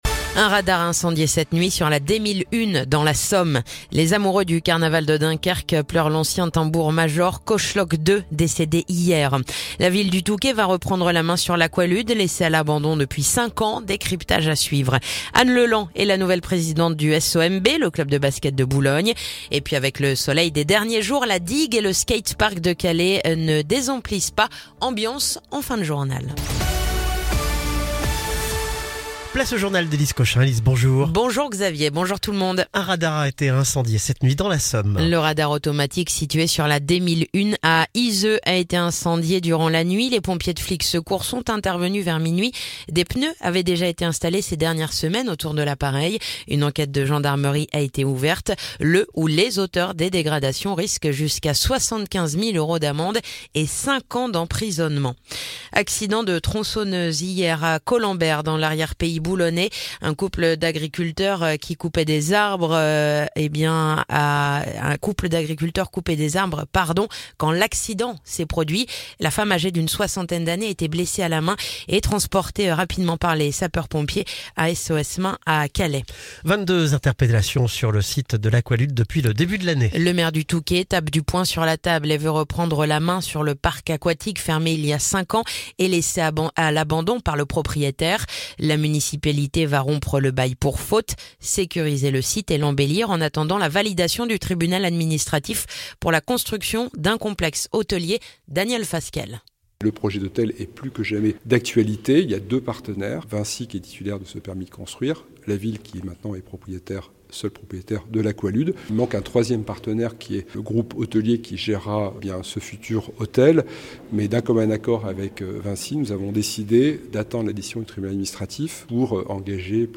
Le journal du jeudi 20 février